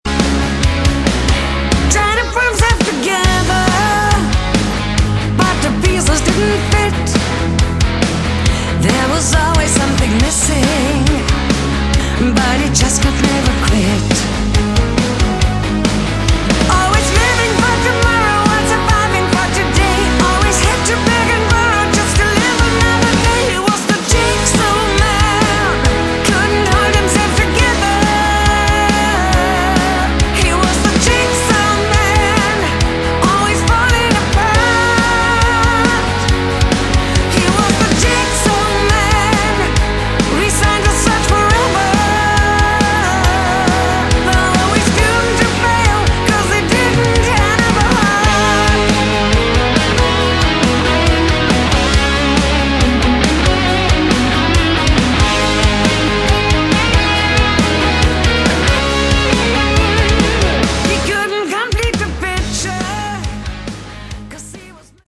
Category: Hard Rock
Vocals, Backing Vocals
Guitar, Bass, Keyboards
Drums, Percussion